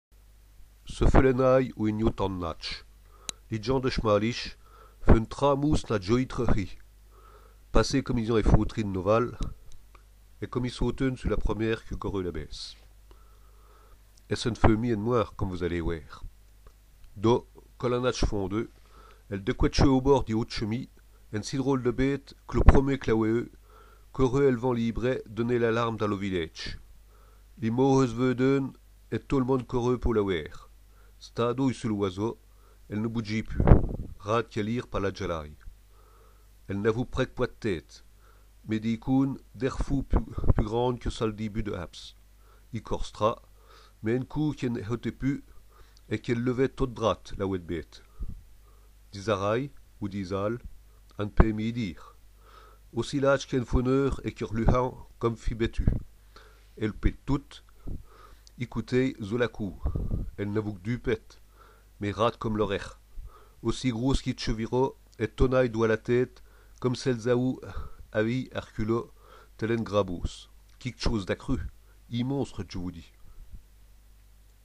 Écoutez causer patois !